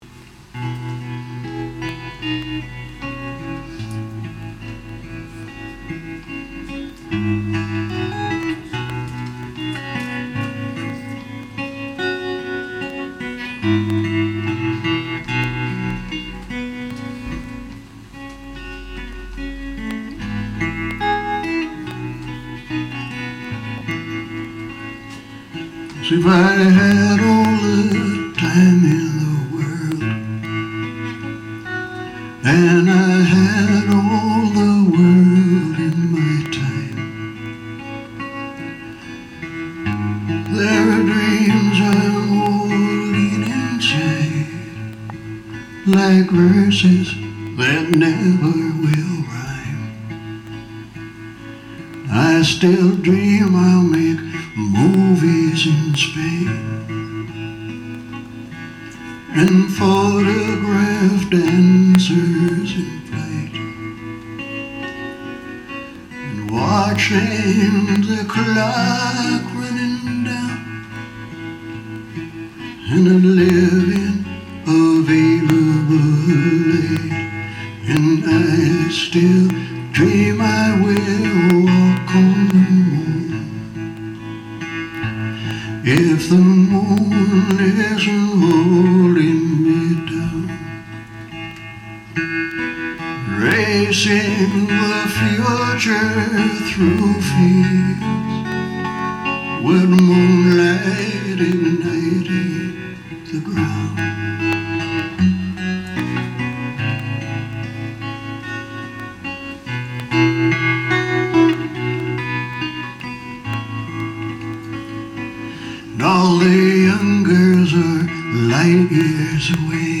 It was Dalry, Scotland and The Turf Inn.